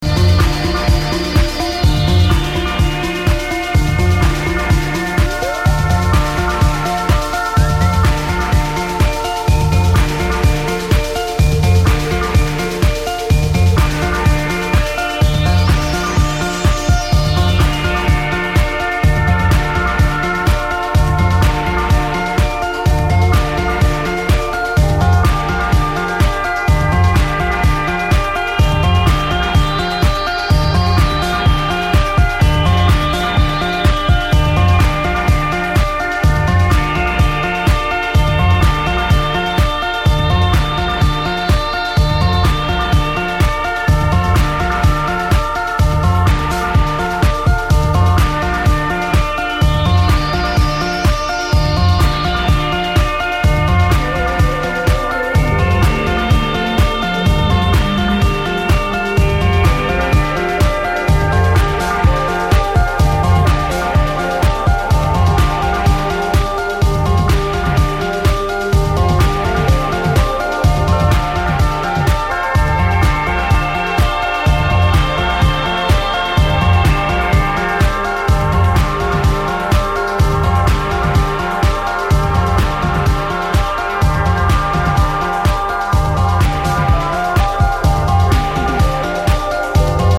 Brooklyn improv techno trio
truly alluring long-form techno